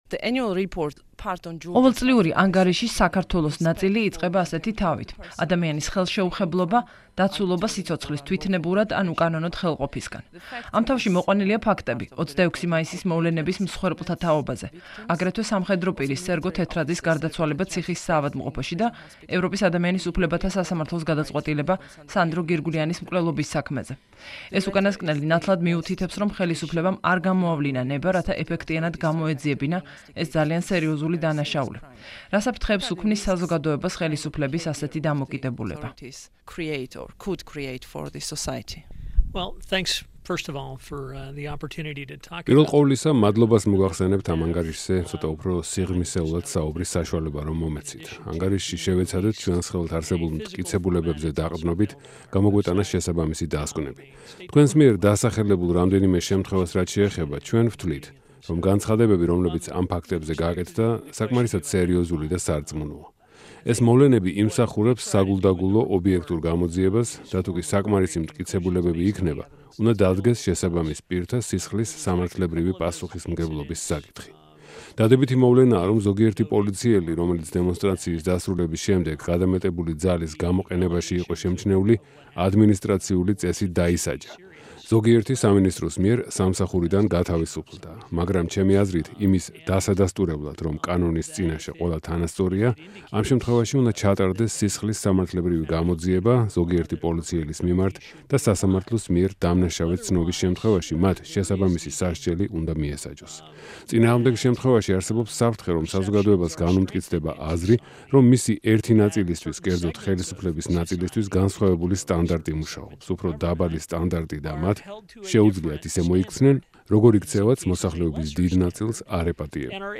საუბარი ჯონ ბასთან (სრული აუდიოვერსია)